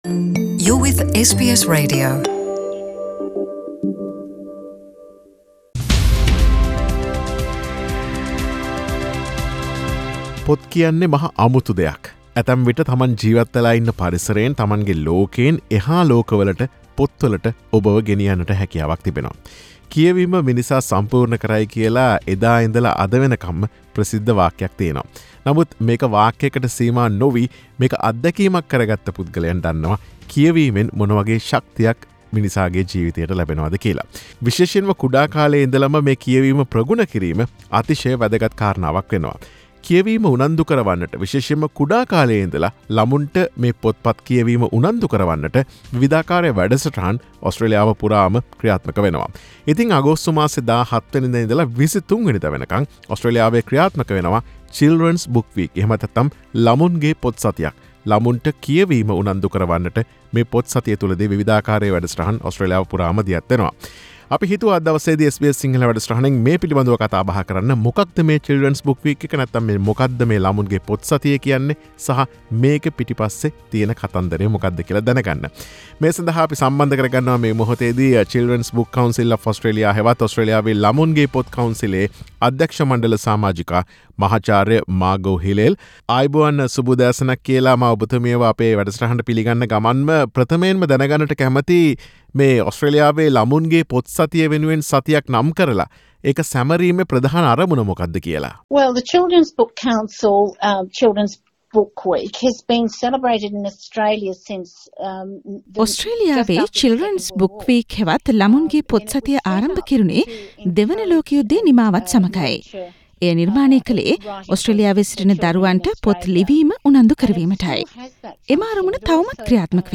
සාකච්ඡාව.